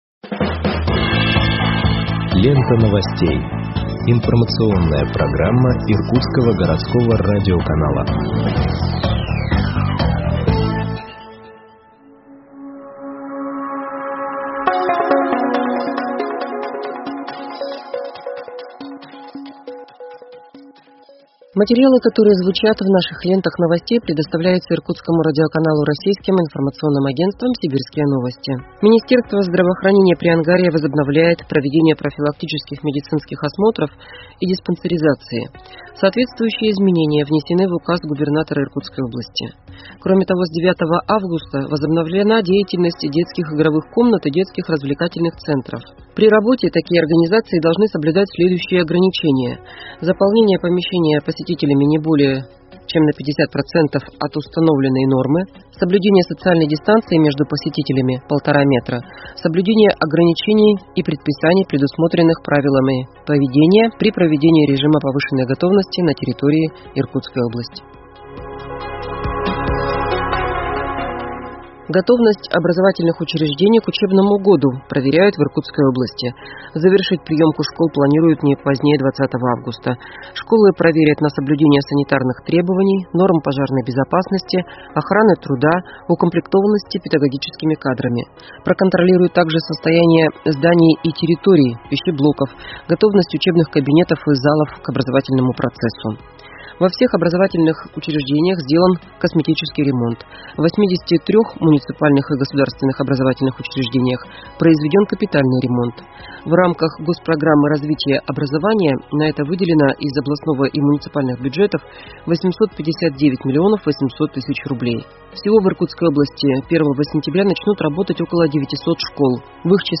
Выпуск новостей в подкастах газеты Иркутск от 11.08.2021 № 1